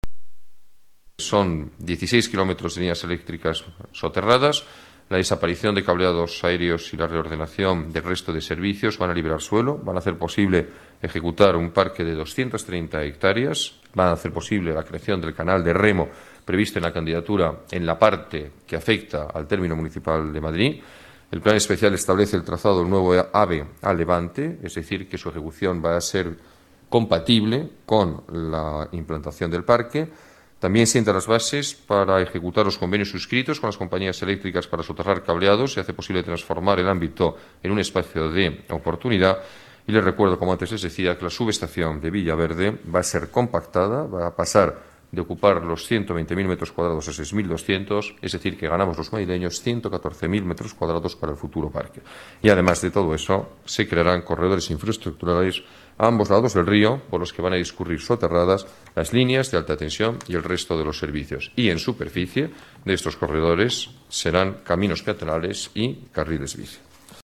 Nueva ventana:Declaraciones alcalde, Alberto Ruiz-Gallardón: Manzanares Sur sin cables de alta tensión